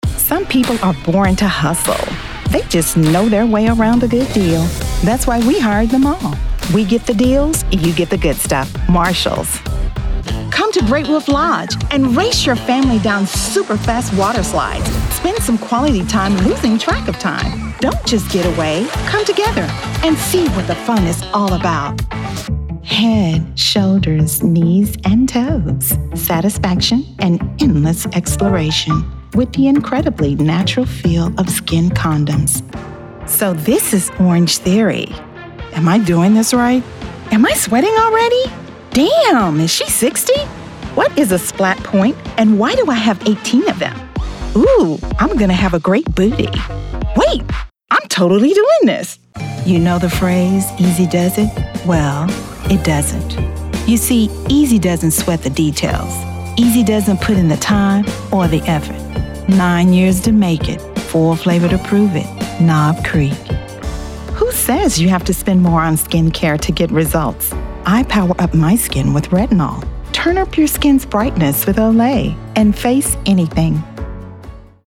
Professional Home Studio
AKG Perception 420 Condenser Microphone
Commercial Demo